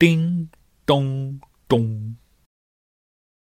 描述：Beatbox创意声音/循环2 bar 135bpm
Tag: 创意 敢-19 循环 口技